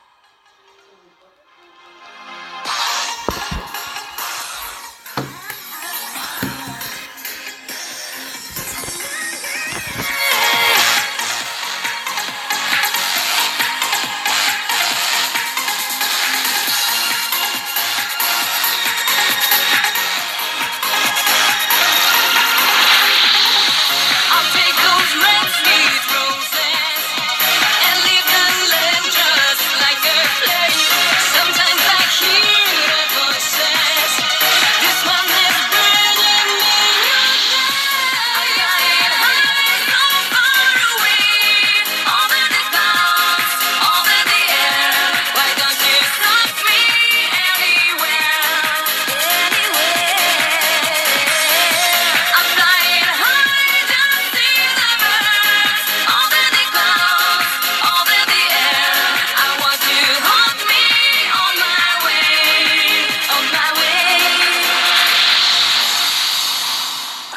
Piosenka z radia - Muzyka elektroniczna
Utwór często jest puszczany w radiu 7 Mława.